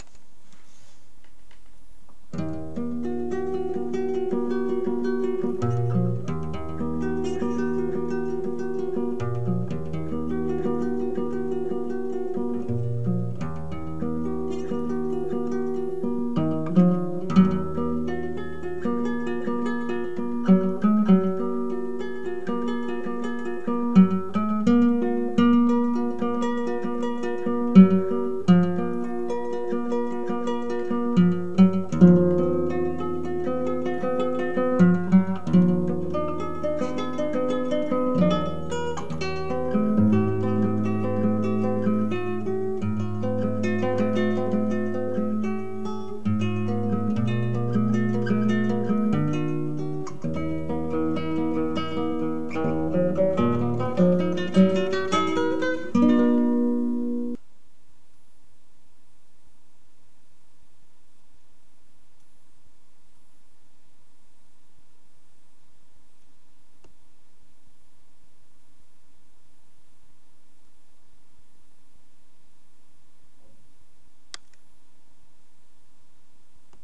Sometimes I try to express myself by playing the guitar.  I have been studying-playing classical guitar for like 10-11 years as an amateur.